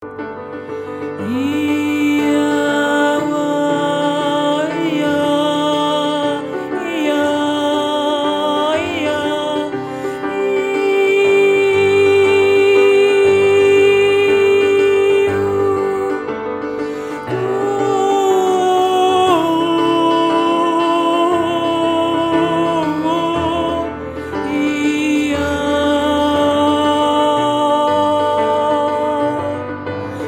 Poesie
Momentum-Aufnahmen